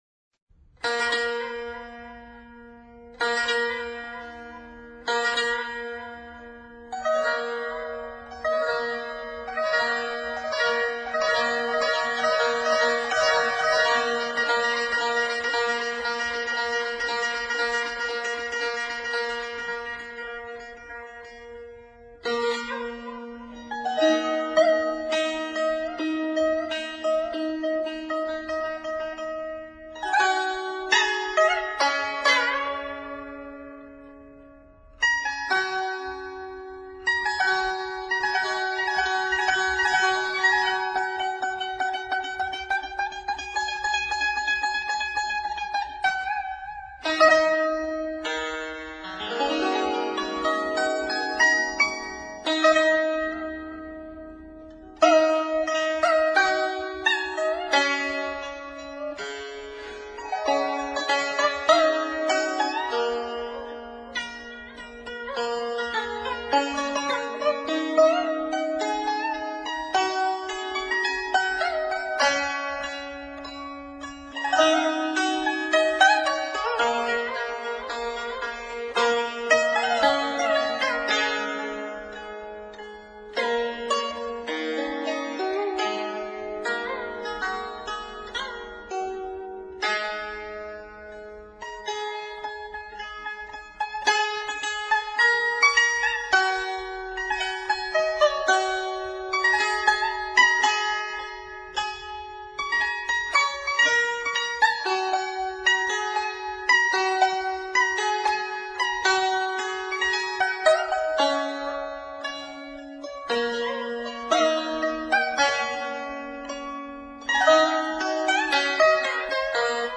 乐曲开头是“江楼钟鼓”，以琵琶模拟鼓声、箫和筝奏出波音，接着响起徐舒优美的旋律，描绘夕阳映江、晚风轻拂的初暮景色。 第二段“月上东山”，主题音调够高四度，旋律向上引发，表达了一种月亮缓缓上升的动感。
第五段“水深云际”，音乐先在低音区回旋，接着八度跳越，并运用颤音和泛音奏出飘逸的音响，表达了水天一色的意境。 第六段“渔歌唱晚”，展现的是一段渔歌的旋律，柔美的箫声如悠扬的渔歌自远处飞来，表现了渔民悠然自得的形象，接着是稍快而有力的乐队合奏，气氛热烈，表达了渔人满载而归的喜悦之情。 第七段“洄澜拍岸”，进入了全曲的第一次高潮。在琵琶用“扫轮”技法奏出强烈的乐声之后，乐队全奏，描绘了群舟竞归，浪花飞溅的情景。 第八段“欸乃归舟”，音乐呈反复式递升，筝划奏的声如流水的历音，速度的由慢而快、力度的由弱至强，表现了波浪层涌、橹声由远渐近的意境。